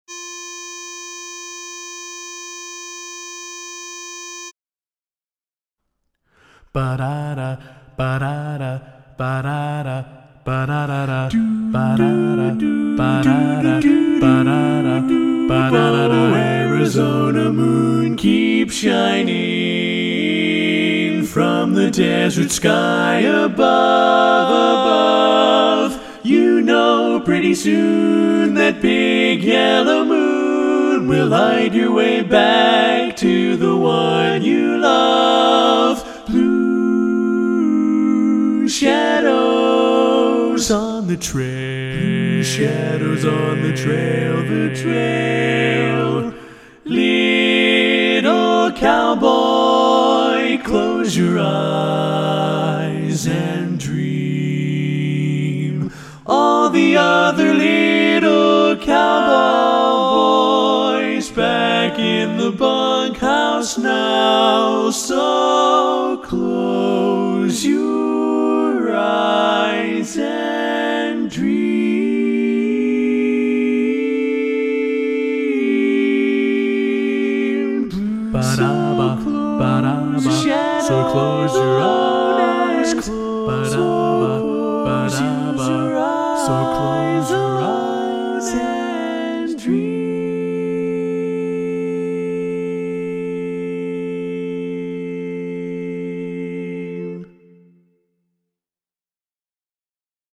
Minus Bass   Sheet Music N/A N/A